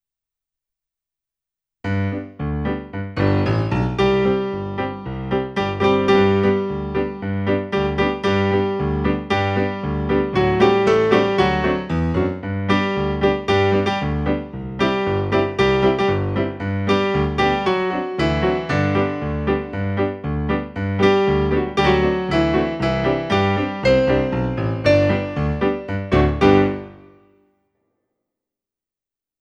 Yellow Rose mezzo-baritone
Yellow-Rose-mezzo-baritone.mp3